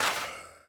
Minecraft Version Minecraft Version snapshot Latest Release | Latest Snapshot snapshot / assets / minecraft / sounds / block / soul_sand / break4.ogg Compare With Compare With Latest Release | Latest Snapshot
break4.ogg